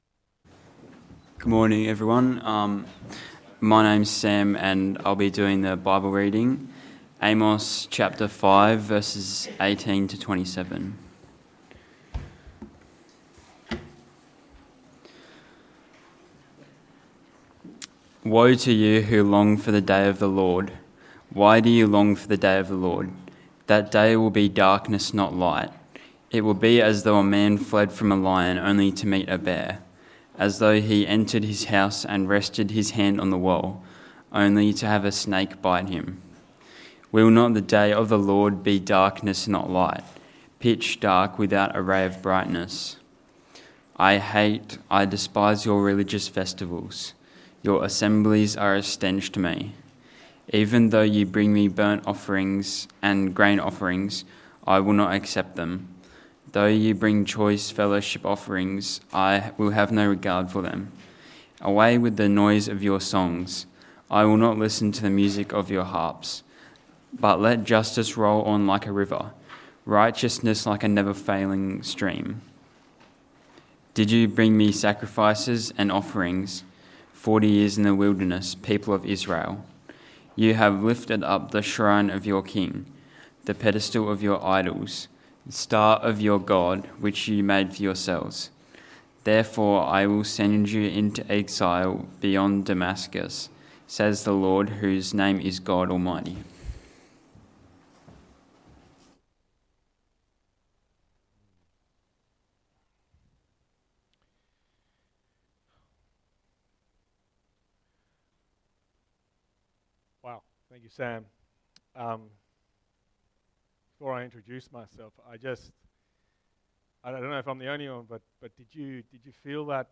Text: Amos 5: 18-27 Sermon